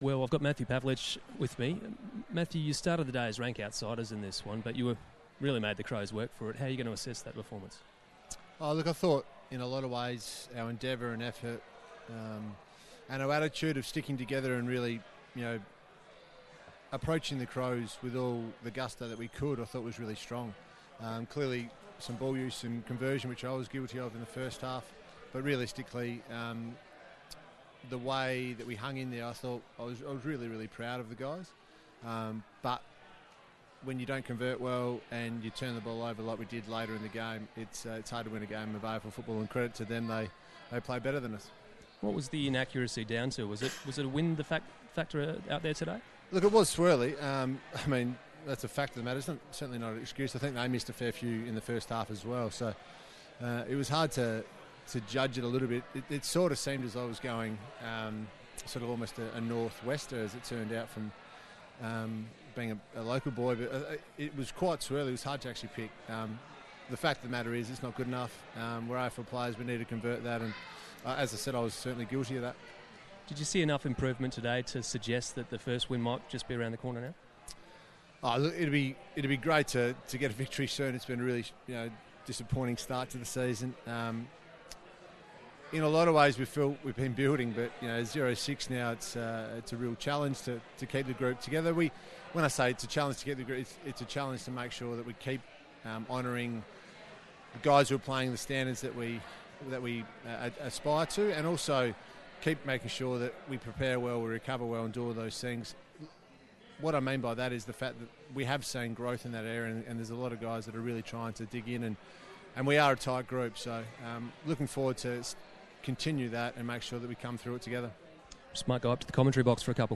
Matthew Pavlich post-match: Rd 6 v Crows
Matthew Pavlich spoke to ABC Grandstand following the loss against Adelaide